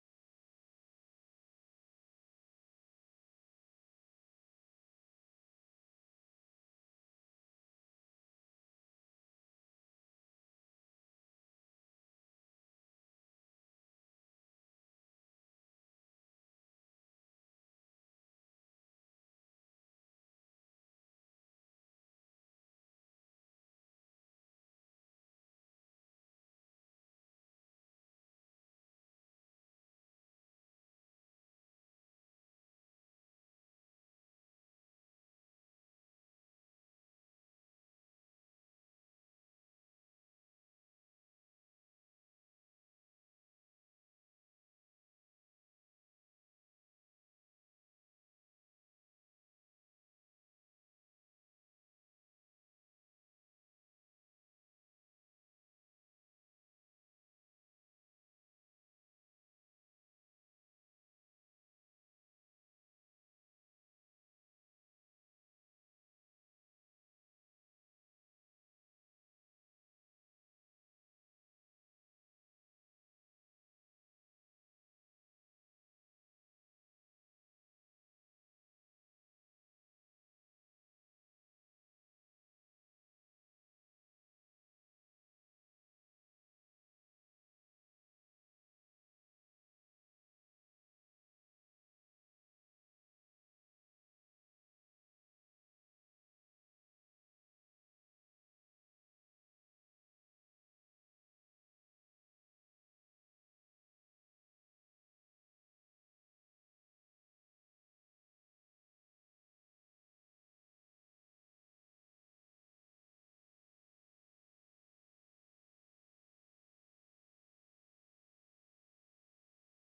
Praise Worship
Prayer Requests, Praises